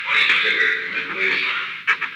Conversation: 917-001
Recording Device: Oval Office
The Oval Office taping system captured this recording, which is known as Conversation 917-001 of the White House Tapes.
The President met with an unknown man.